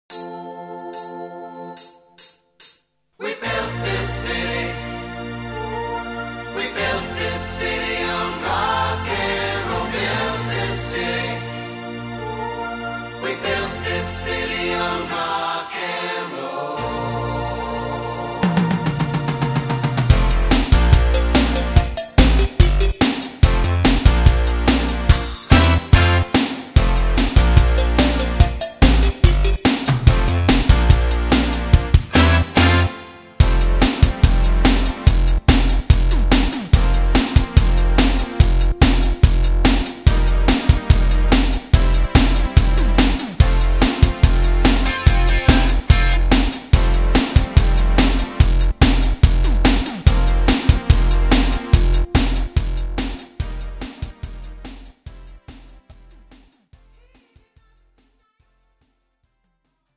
(팝송) MR 반주입니다.